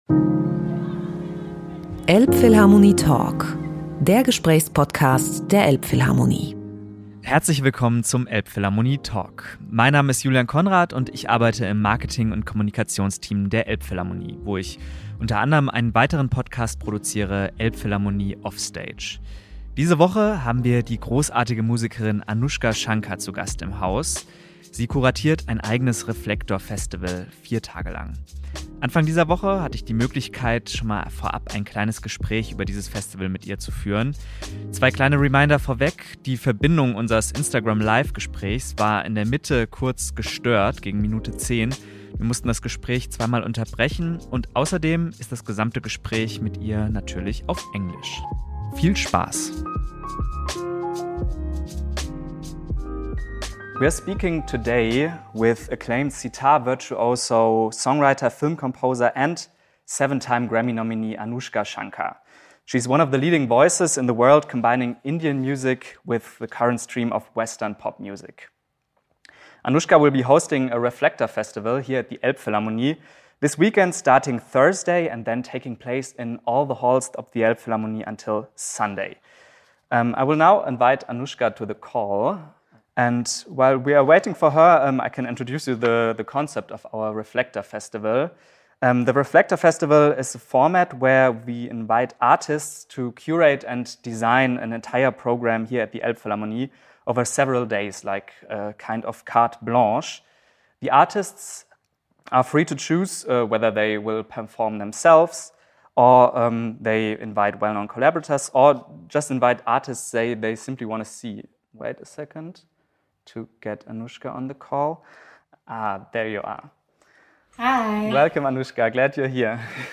Letzte Episode Elbphilharmonie Talk mit Anoushka Shankar 3. November 2021 Nächste Episode download Beschreibung Teilen Abonnieren *In English Language* Ein paar Tage bevor ihr Reflektor-Festival in der Elbphilharmonie startet, konnten wir mit Anoushka Shankar über Instagram Live einen ausführlichen Blick auf ihr Festival-Programm werfen. Hier gibt es das Gespräch als Podcast zum Nachhören!